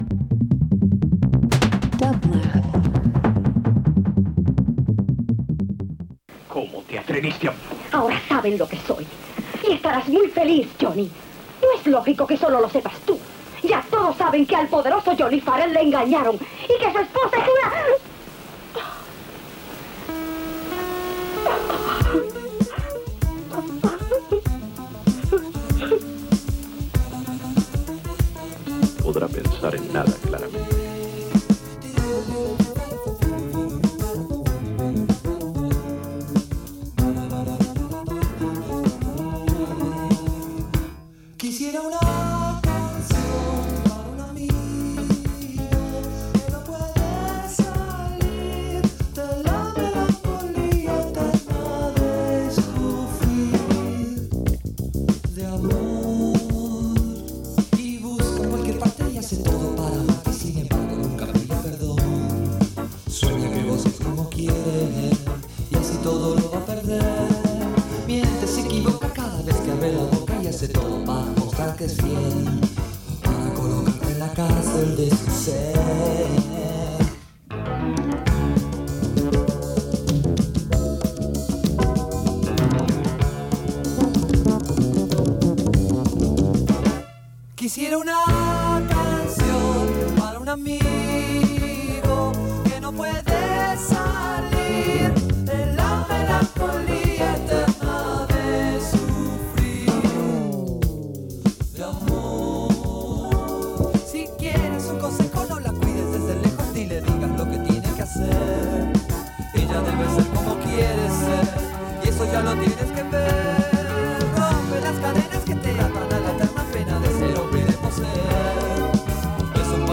Jazz R&B Rock